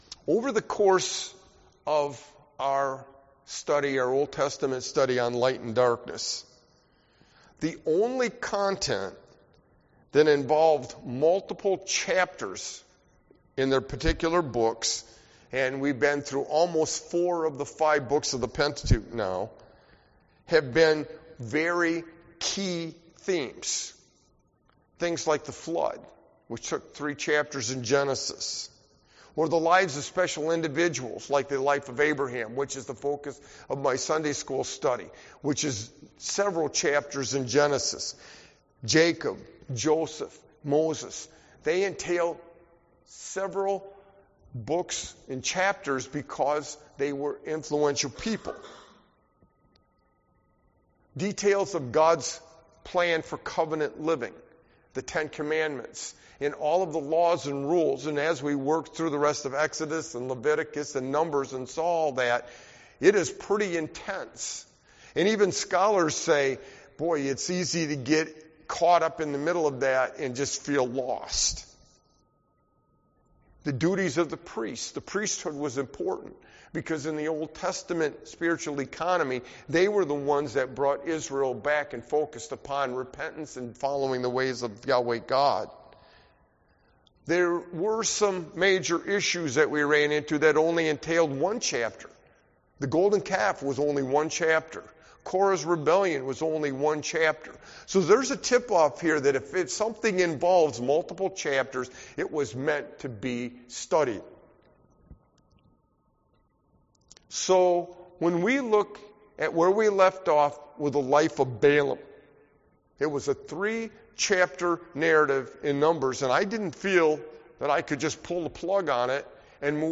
Sermon-Light-and-Darkness-LXXVI-62622.mp3